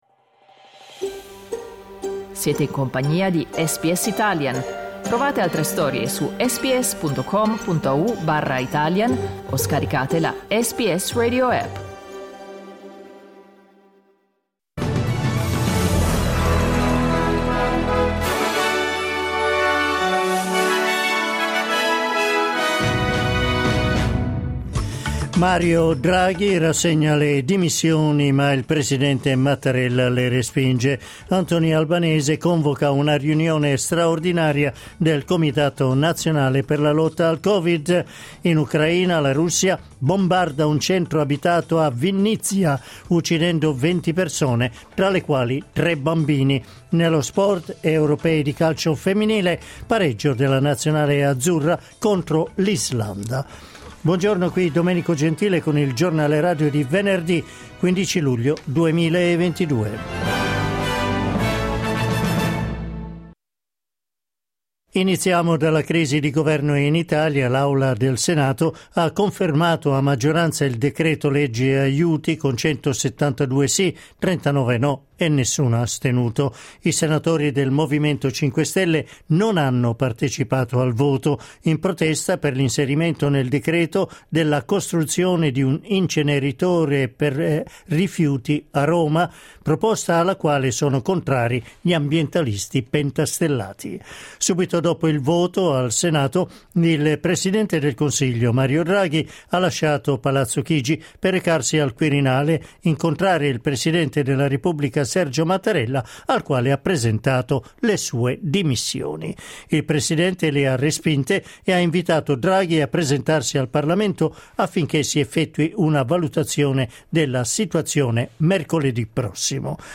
Giornale radio venerdì 15 luglio 2022
Il notiziario di SBS in italiano.